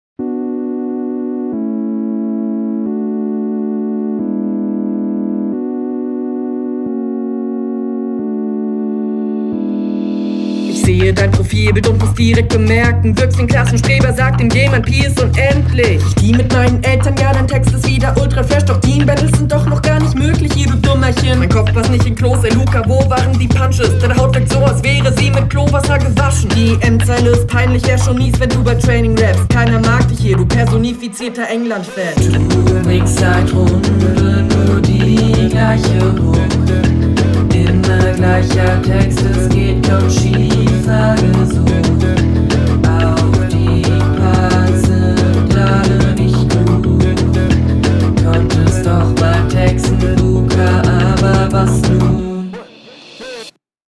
Mische schlechter als Gegner, Beat ist sehr laut.